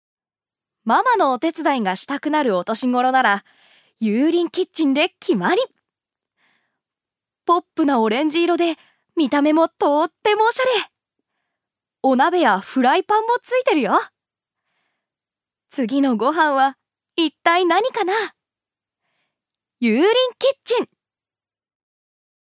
ジュニア：女性
ナレーション２